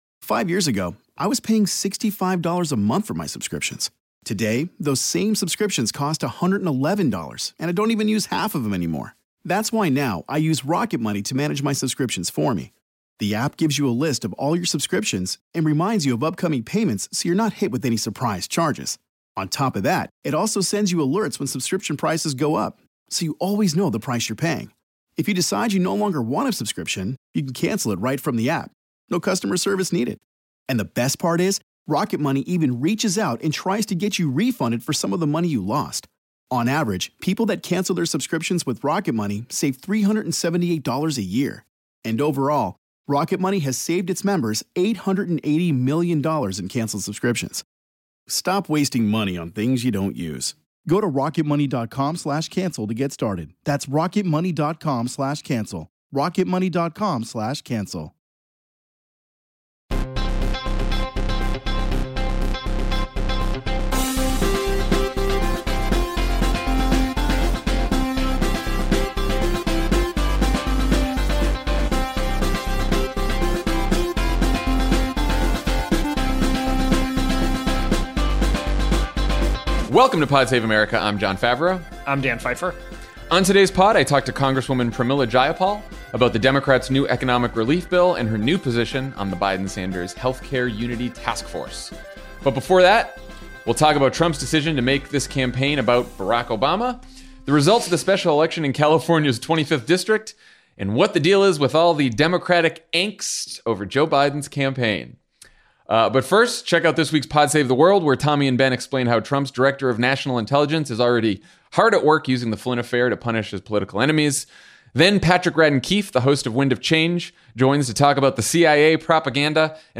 Trump tries to make the election about Barack Obama, Democrats come up short in a California special election, Joe Biden announces a set of policy unity task forces, and Democratic strategists offer his campaign plenty of advice. Then Congresswoman Pramila Jayapal talks to Jon about her Paycheck Guarantee Act and her role as the co-chair of Biden’s health care unity task force.